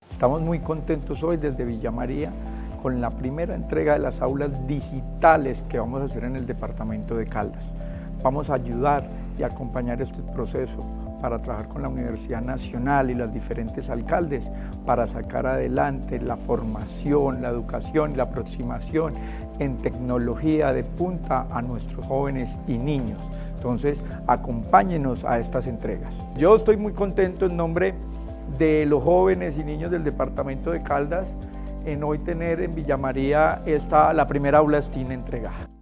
Secretario de Educación, Luis Herney Vargas Barrera.
Secretario-de-Educacion-Luis-Herney-Vargas-aulas-STEAM.mp3